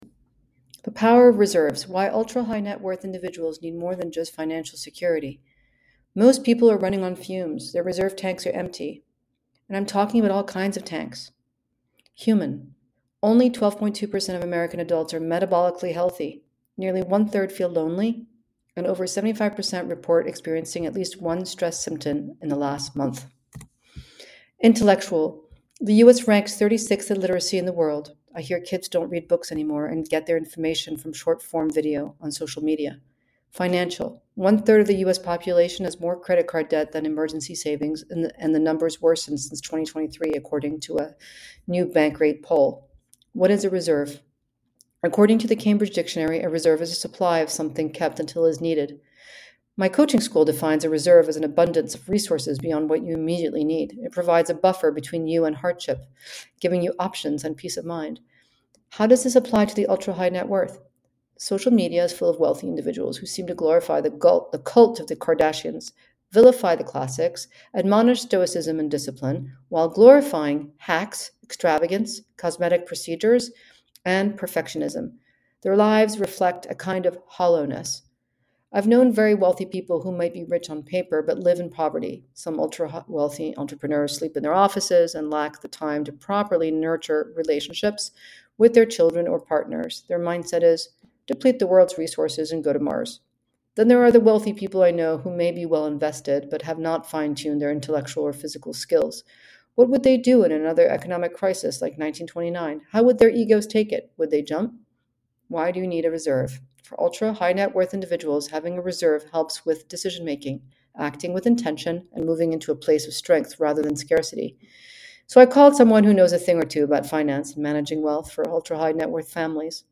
A narrated essay from The Pressures of Privilege.